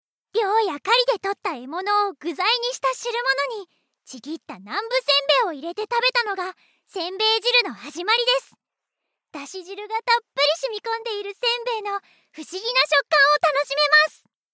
ボイスサンプル
キャラナレーション